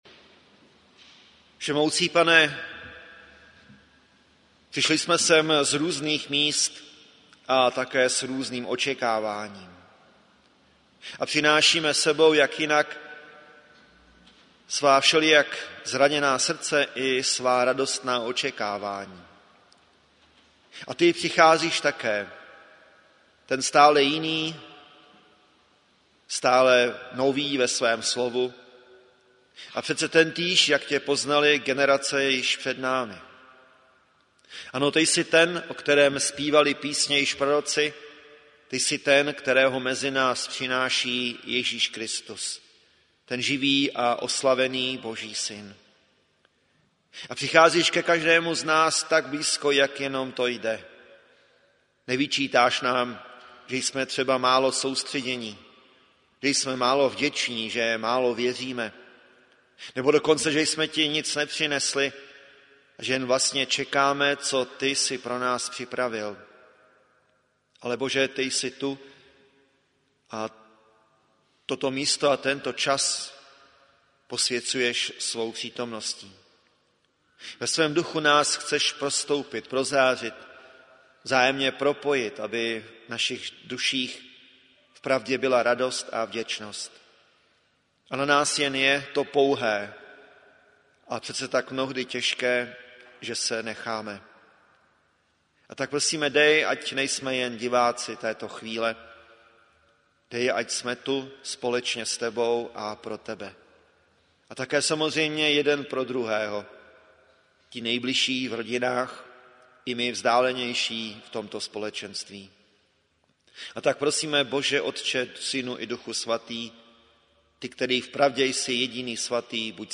Rodinná neděle
modlitba